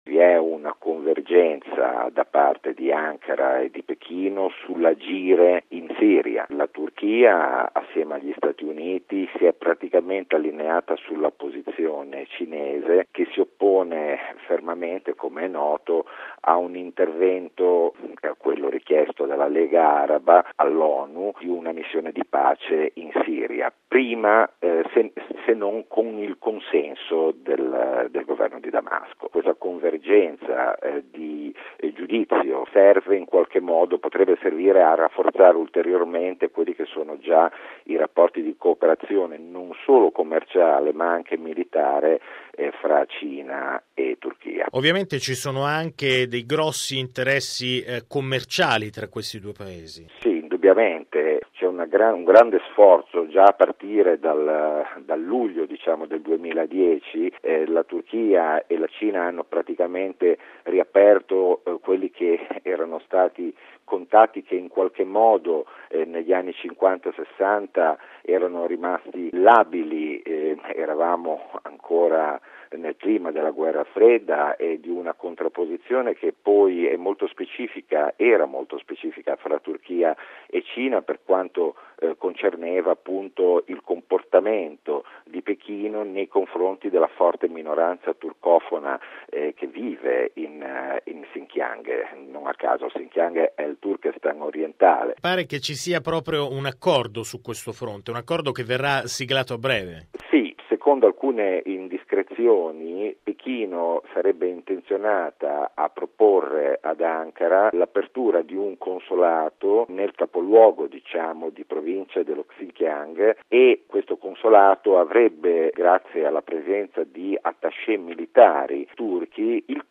esperto di questioni turche:RealAudio